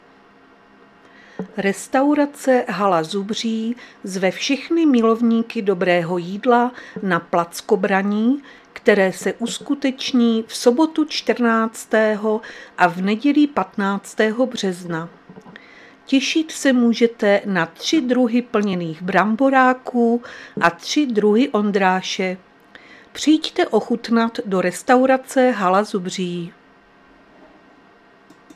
Záznam hlášení místního rozhlasu 12.3.2026
Zařazení: Rozhlas